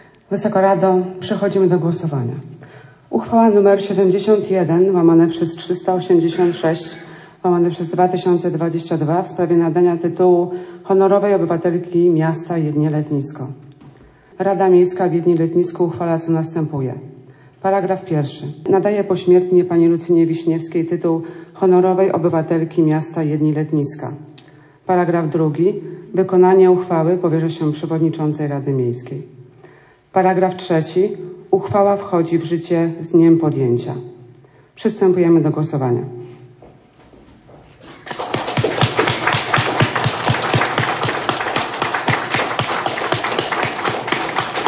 Głosowanie podczas Nadzwyczajnej Sesji poprowadziła Przewodnicząca Rady Miasta Jedlni – Letniska Sylwia Moskwa